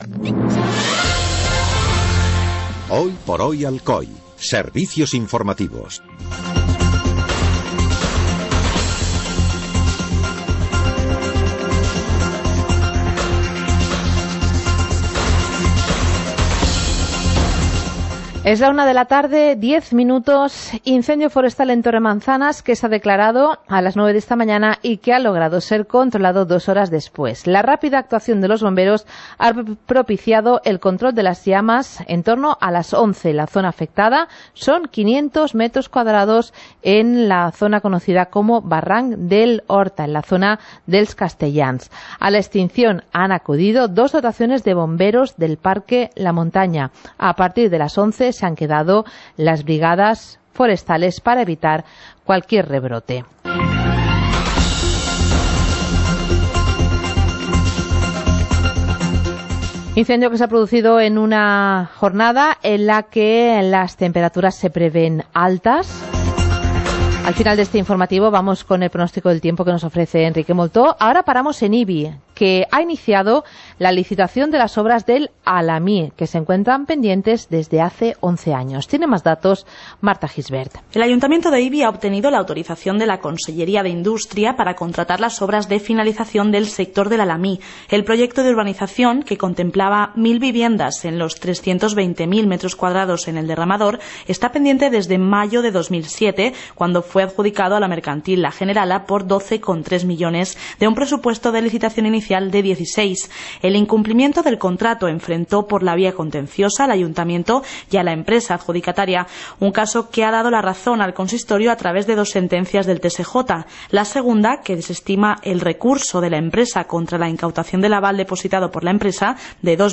Informativo comarcal - martes, 31 de julio de 2018